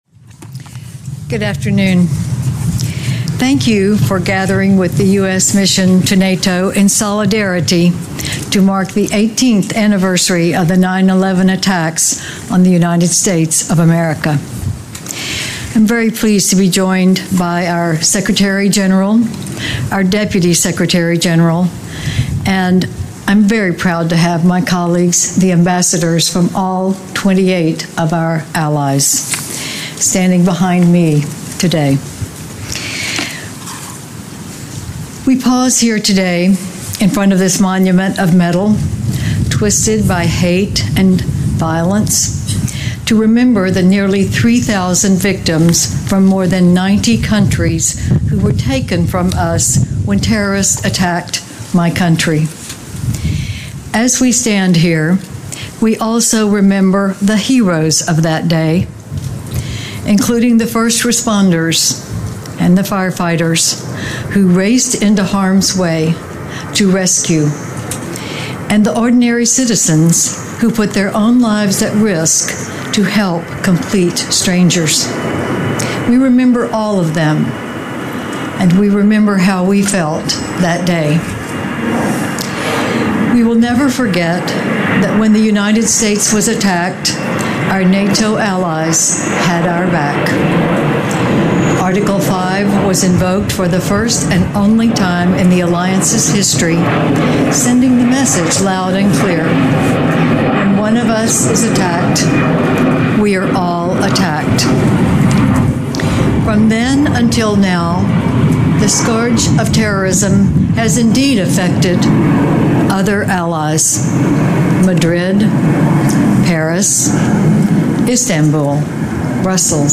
Kay Bailey Hutchison - 9/11 Attacks Remembrance Ceremony Speech at NATO
delivered 11 September 2019, NATO HQ, Brussels, Belgium
Audio Note: AR-XE = American Rhetoric Extreme Enhancement